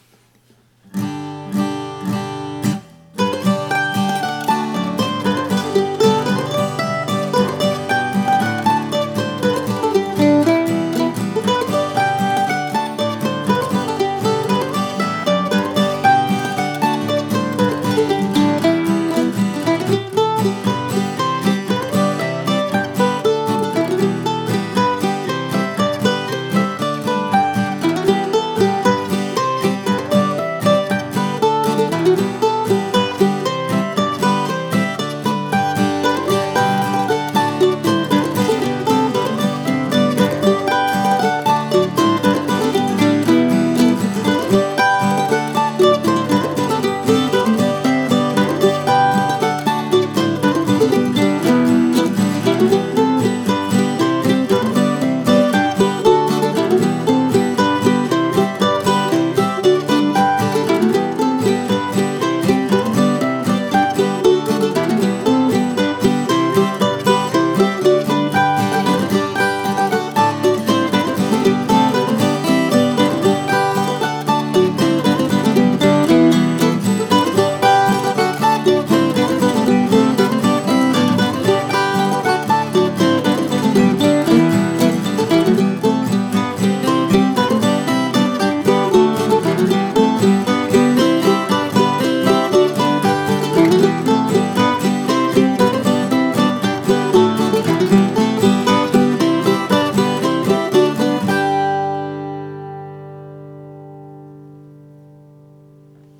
I wrote this little polka the week before